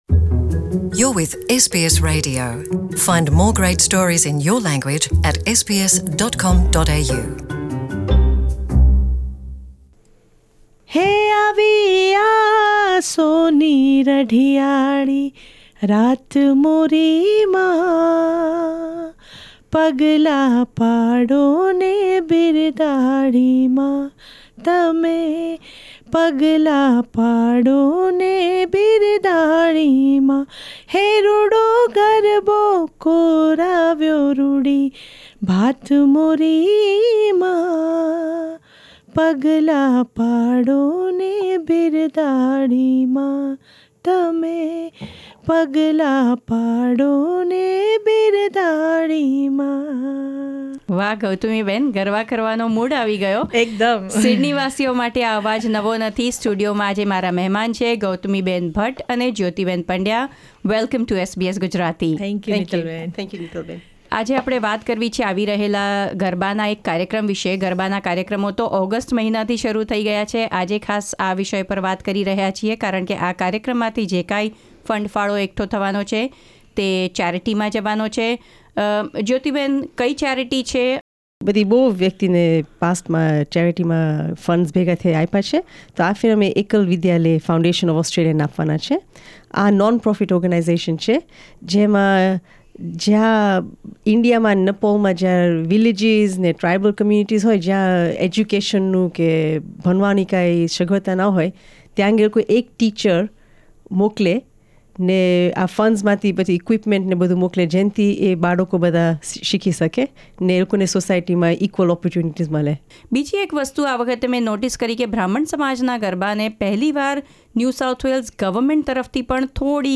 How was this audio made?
at SBS Studio in Sydney